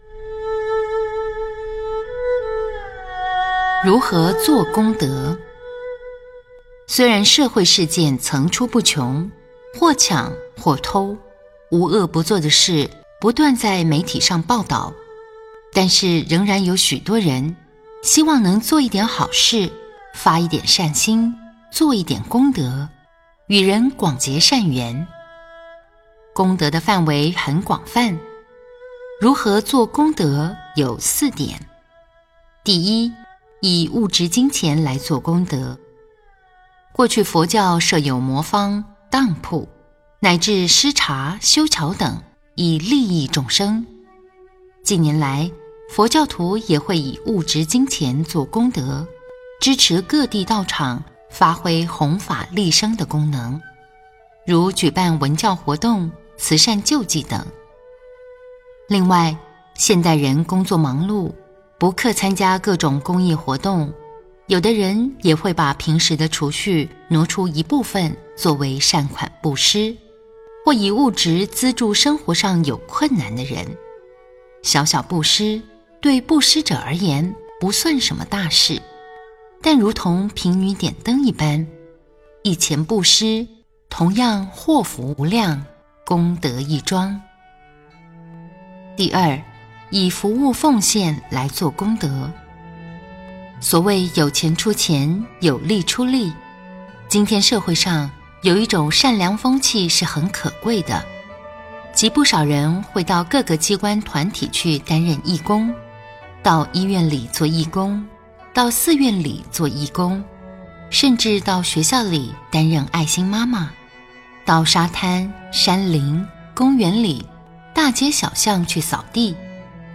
92.如何做功德--佚名 冥想 92.如何做功德--佚名 点我： 标签: 佛音 冥想 佛教音乐 返回列表 上一篇： 88.平等法--佚名 下一篇： 93.如何佛光普照--佚名 相关文章 普贤行愿品--普寿寺 普贤行愿品--普寿寺...